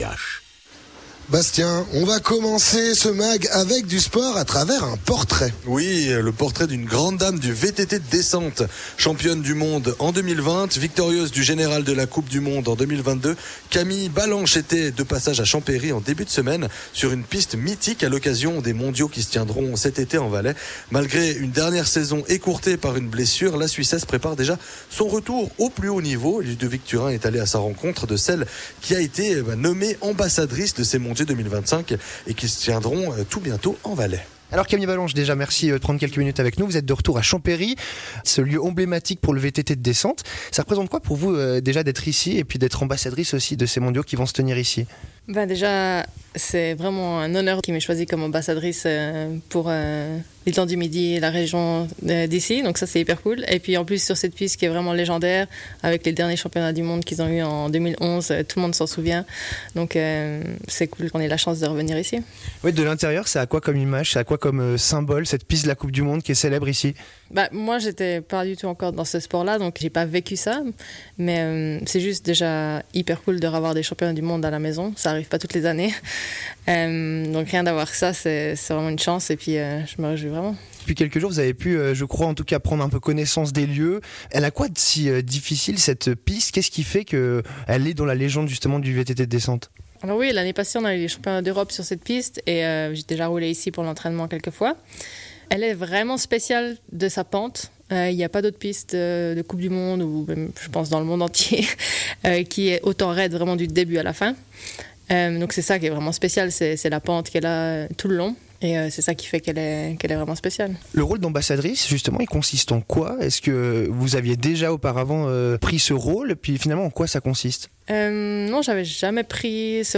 Entretien avec Camille Balanche, spécialiste du VTT de descente